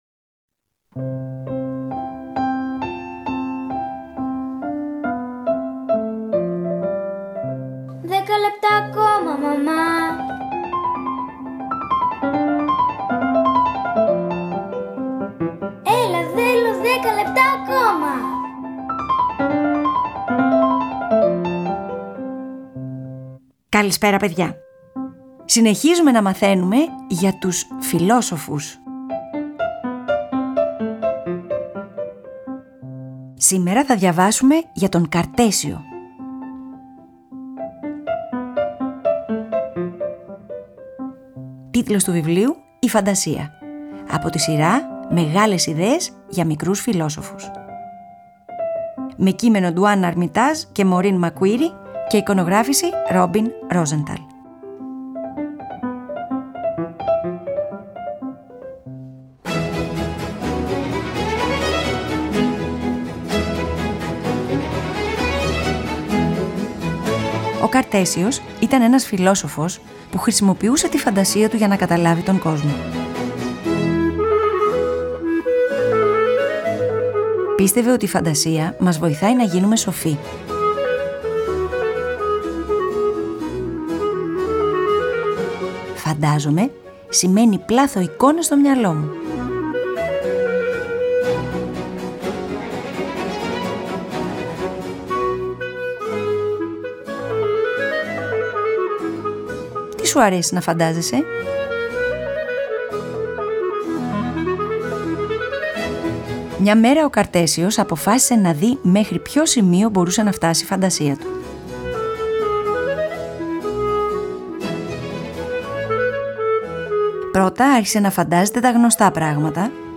Antonio Vivaldi, concerto for clarinet and orchestra No.3